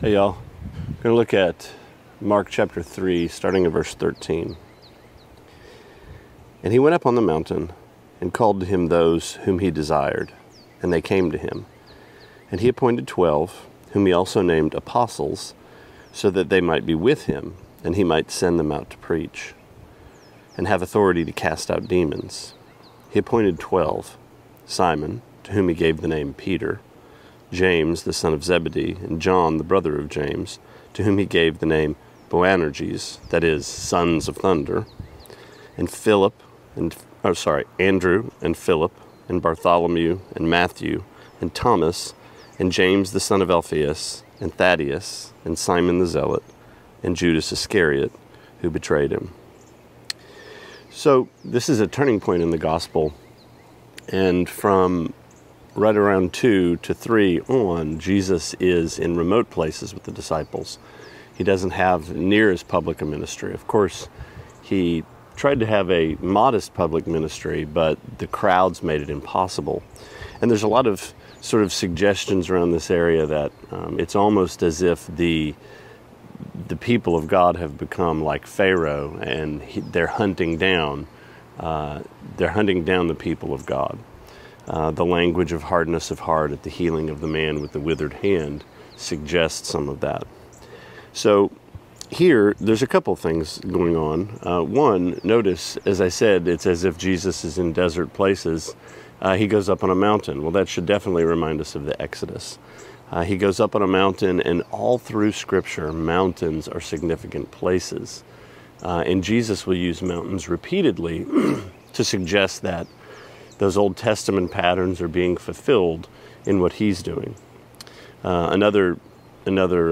Sermonette 6/21: Mark 3:13-19: Exodus Again